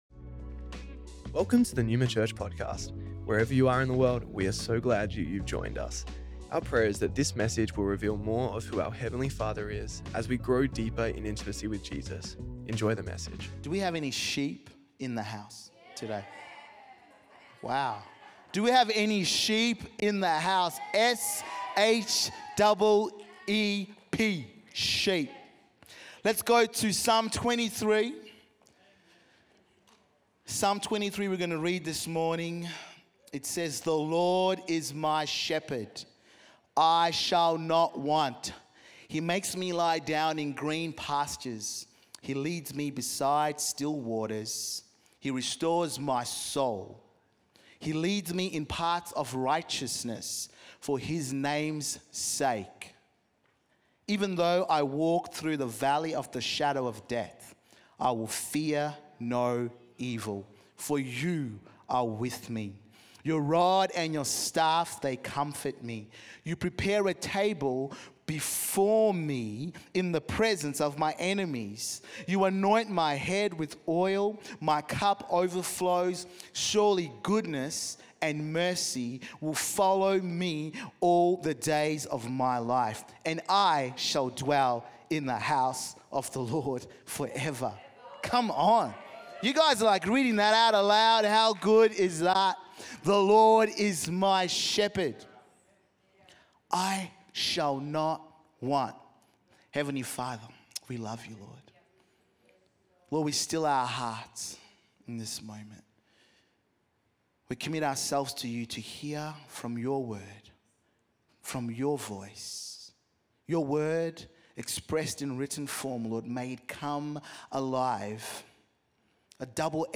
Neuma Church Melbourne South Originally Recorded at the 10AM Service on Sunday 1st December 2024